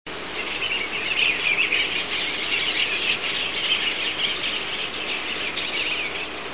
Eastern Bluebird
Gaisler Road, Delaware Water Gap, Blairstown, New Jersey, family chatter 6/4/01 (kb26).  This was flock of approximately 20 birds.